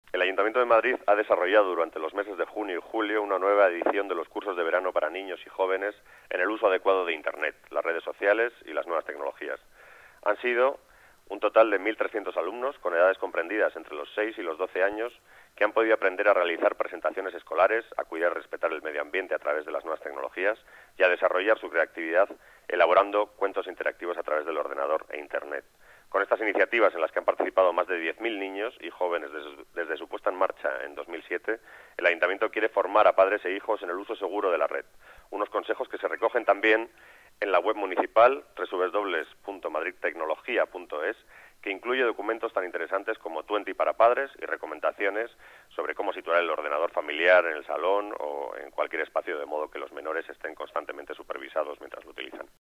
Nueva ventana:Declaraciones del coordinador general de Economía, Javier Oyarzábal